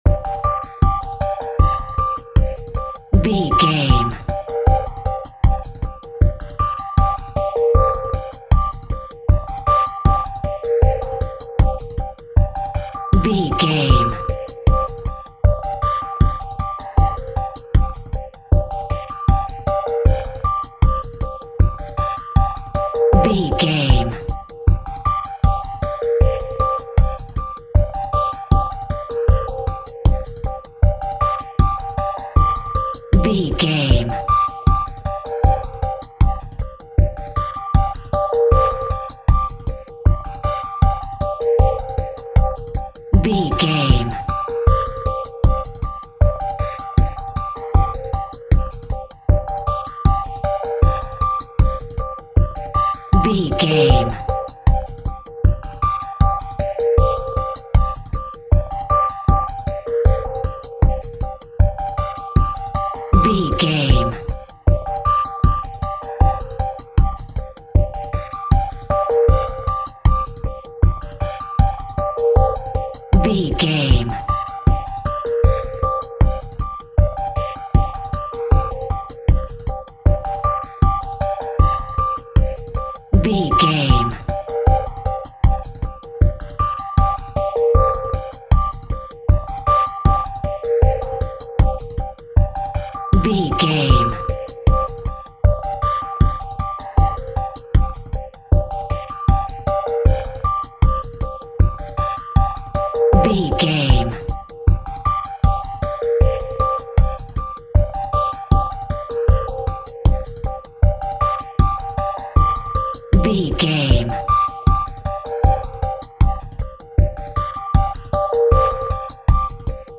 Aeolian/Minor
Slow
futuristic
hypnotic
industrial
mechanical
dreamy
smooth
contemplative
drum machine
synthesiser
electronic
trance
drone
glitch
synth drums
synth leads
synth bass